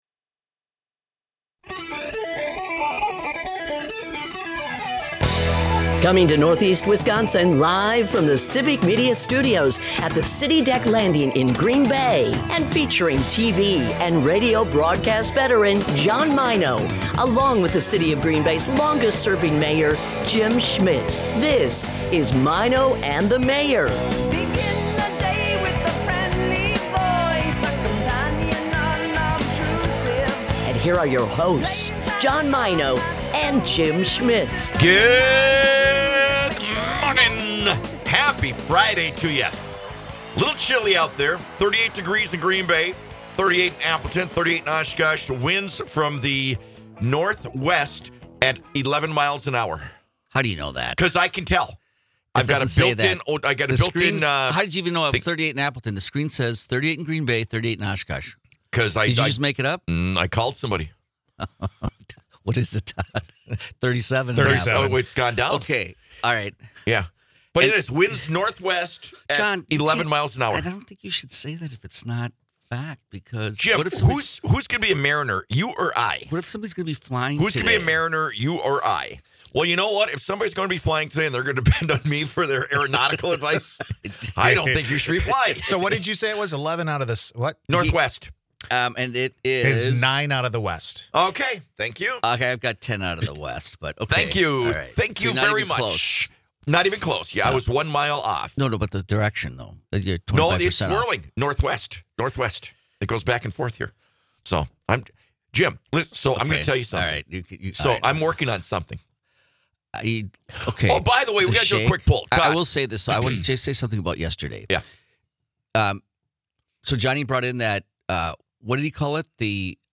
As the guys get ready to kick off the weekend, they discuss picking up trash during Green Bay's Trash Bash yesterday, ways of making money as kids, and they take a poll: Should they be nicer to one another, or should they keep giving each other the business?